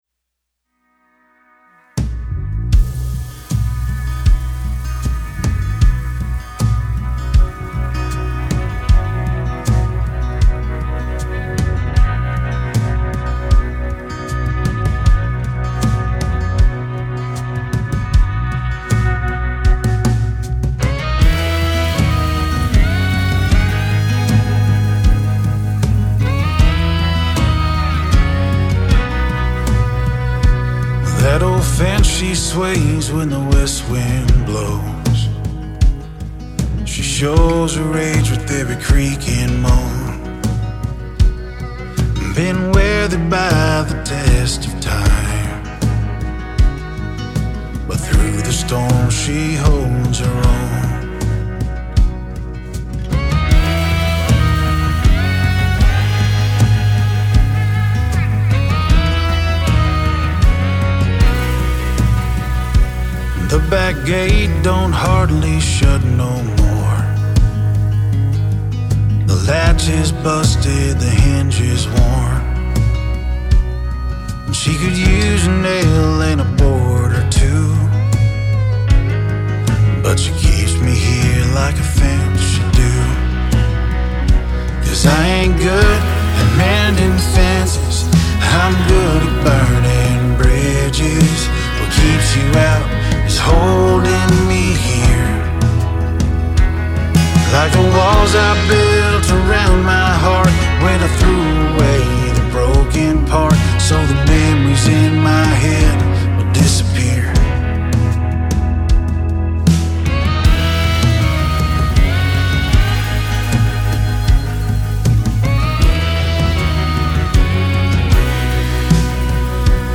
vocals
Acoustic guitar, electric guitar, keys
Acoustic guitar, fiddle, electric guitar, banjo
Drums